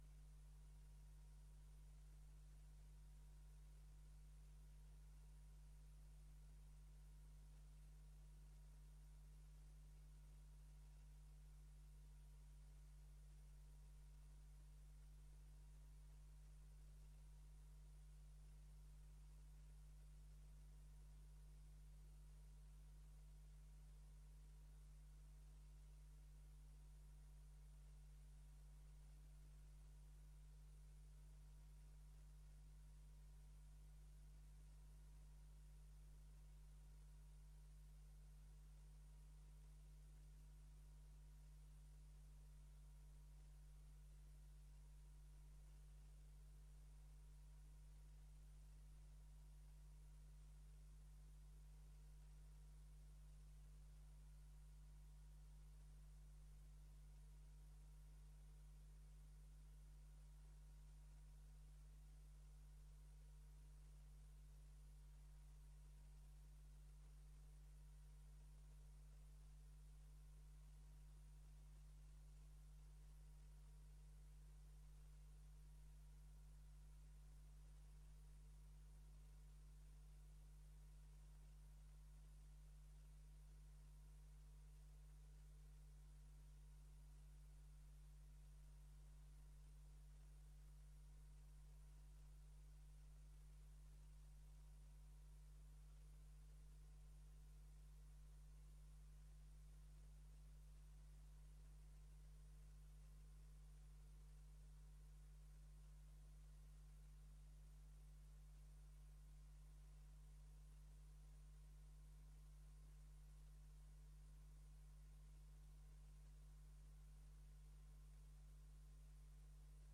Download de volledige audio van deze vergadering
Locatie: Raadzaal Voorzitter: H.A.J. Kleine Koerkamp